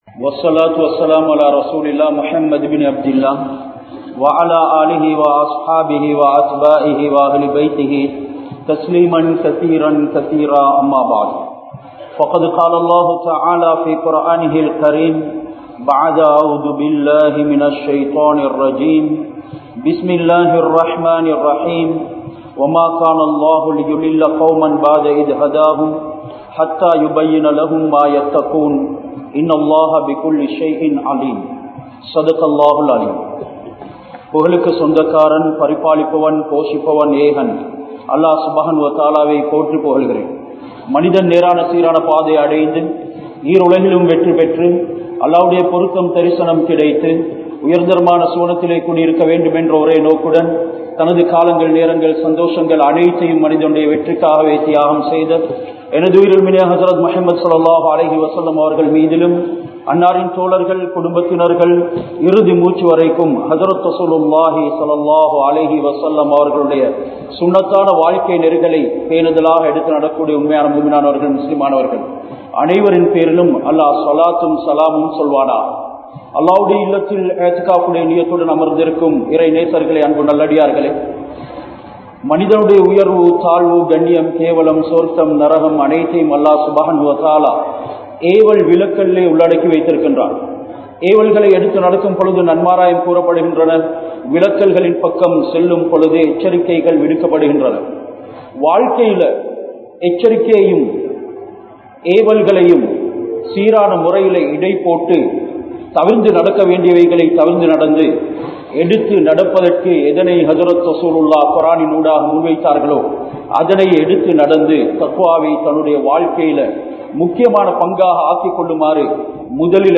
Hadhith Jibreel(Alai)in Ulladakkam Enna? (ஹதீஸ் ஜிப்ரீல்(அலை)யின் உள்ளடக்கம் என்ன?) | Audio Bayans | All Ceylon Muslim Youth Community | Addalaichenai